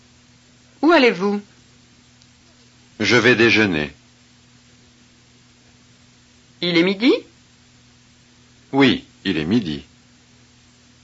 Chapter 1 reference, with pauses: